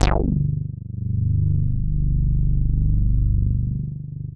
G2_moogy.wav